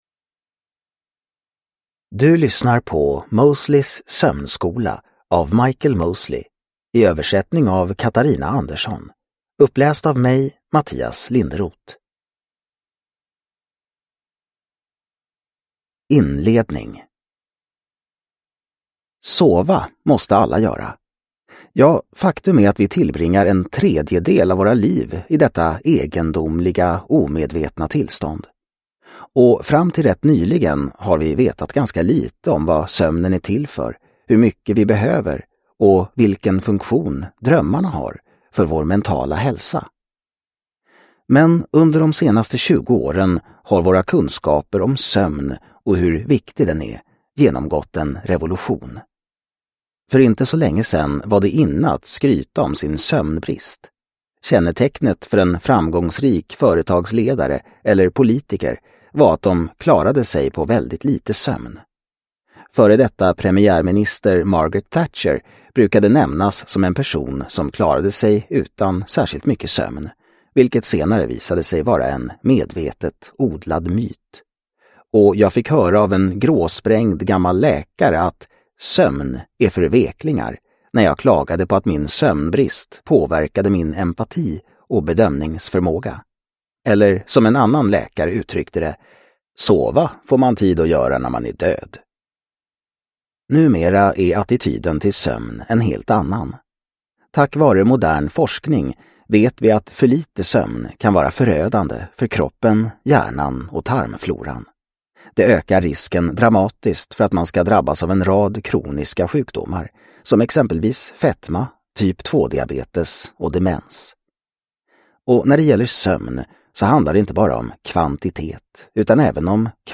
Mosleys sömnskola : fyraveckorsprogram till bättre sömn och hälsa – Ljudbok – Laddas ner